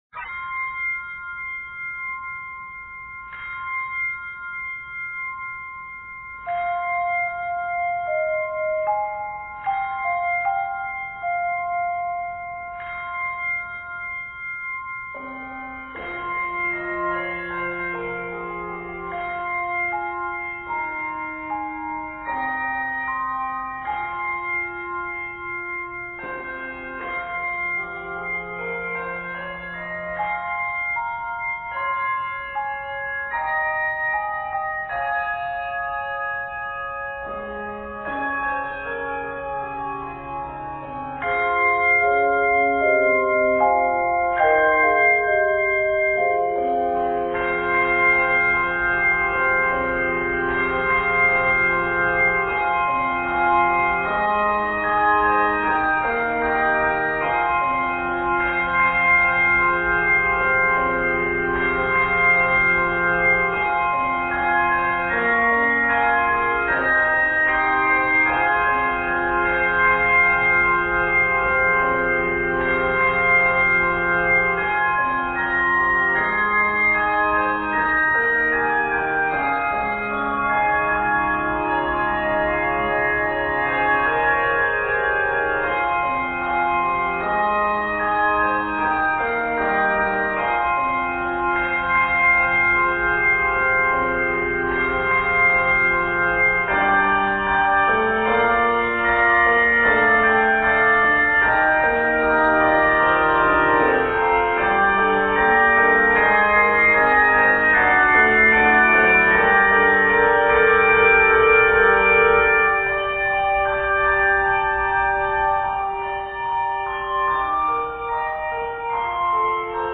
Arranged in f minor and g minor, this piece is 73 measures.